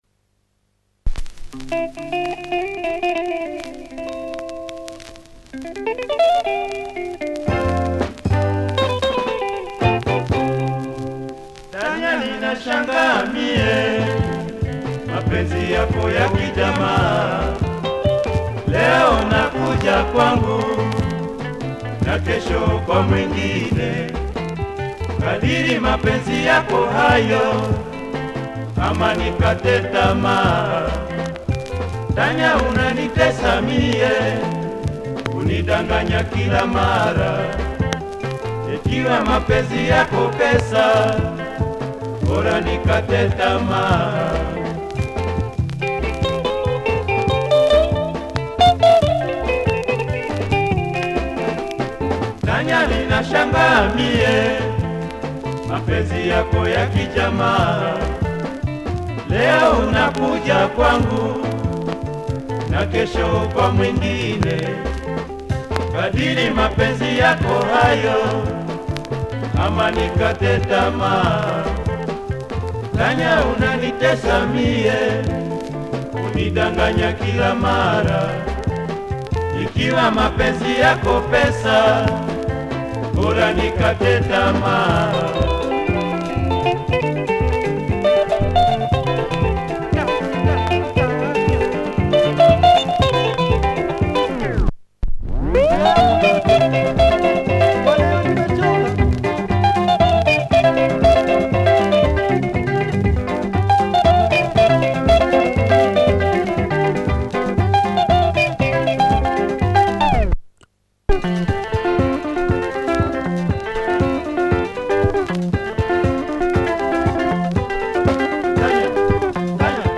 Swahili soukous